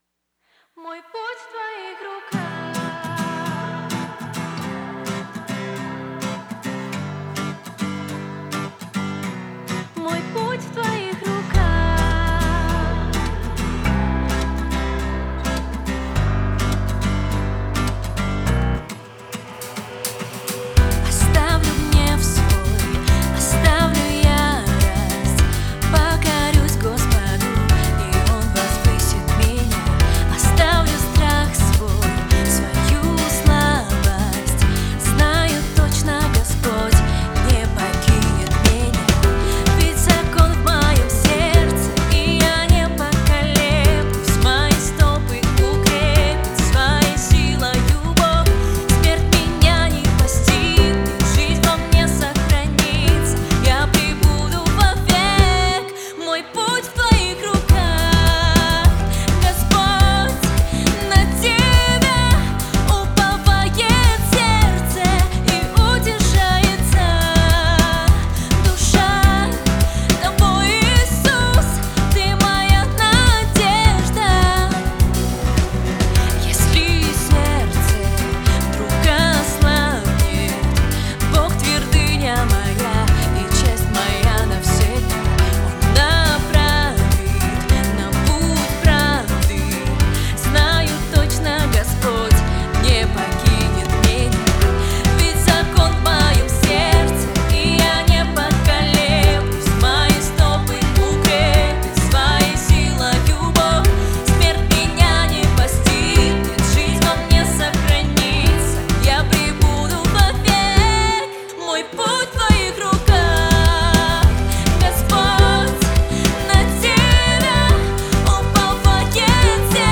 песня